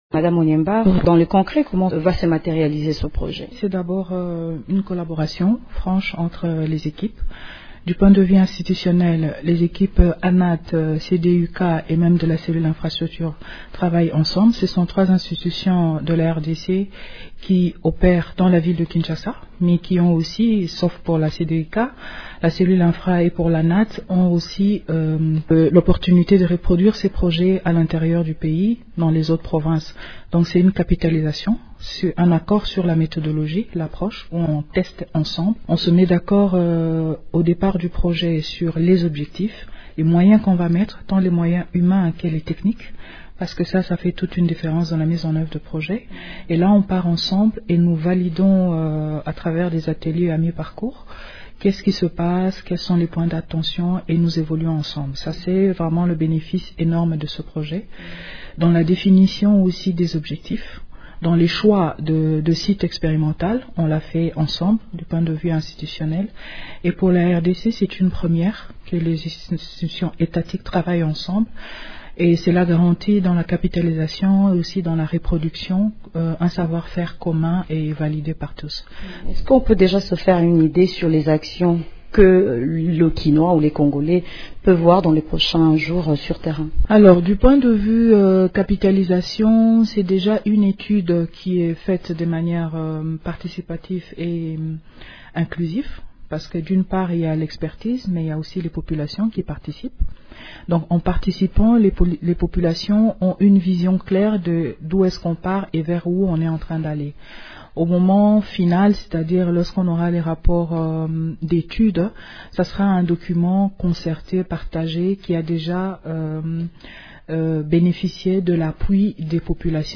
Pour en parler, Radio Okapi reçoit Karine Onyemba, directrice générale de l’ANAT.